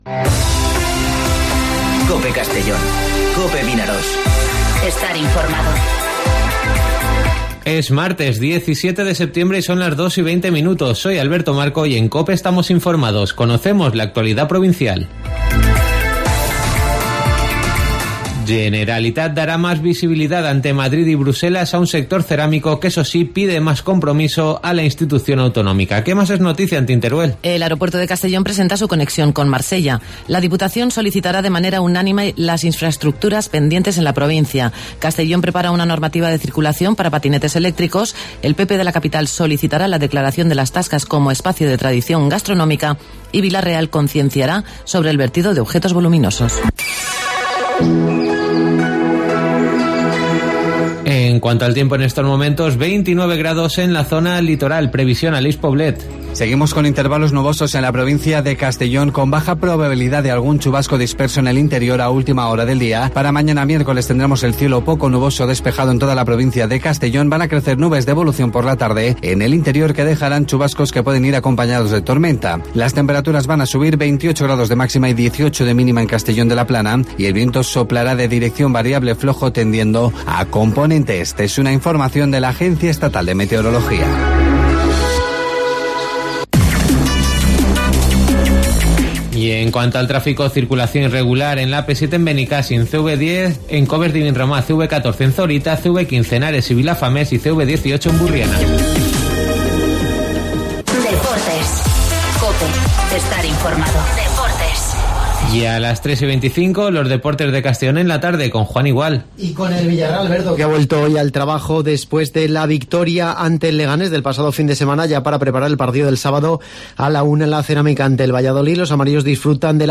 Informativo Mediodía COPE en Castellón (17/09/2019)